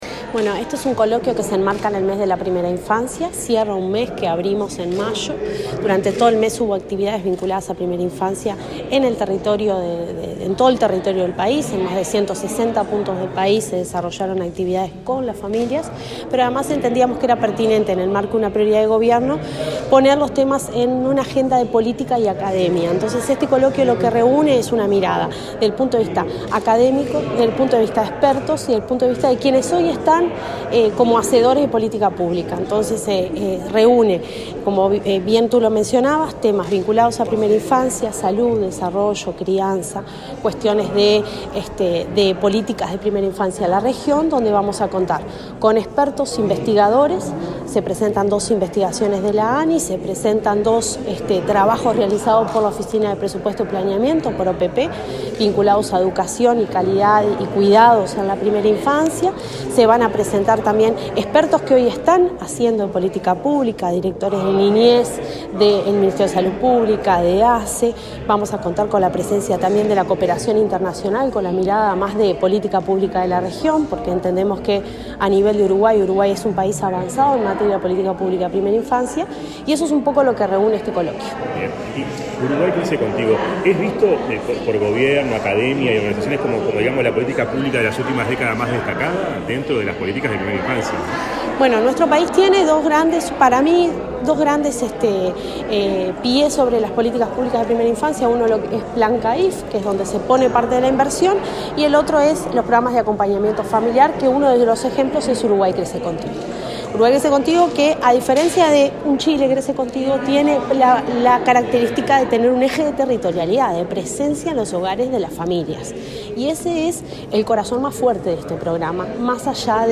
Declaraciones a la prensa de la directora de Desarrollo Social, Cecilia Sena
Luego, la directora Sena dialogó con la prensa.